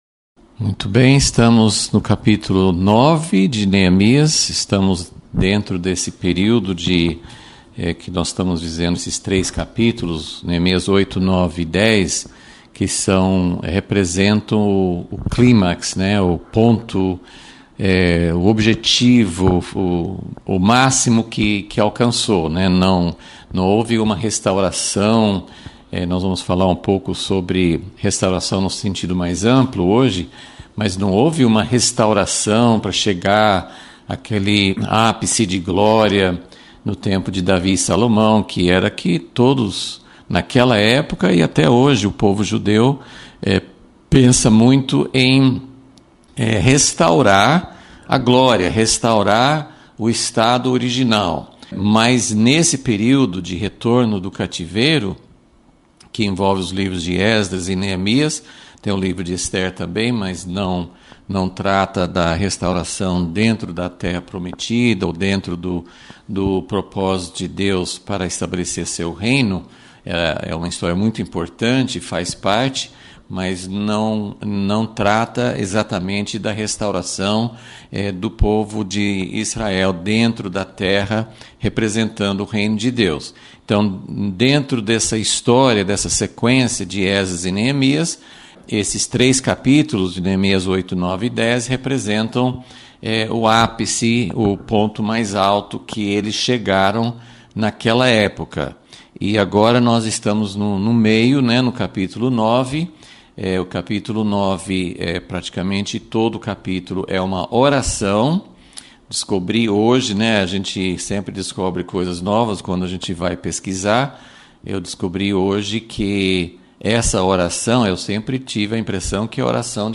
Aula 23 – Vol.36 – O verdadeiro sentido da restauração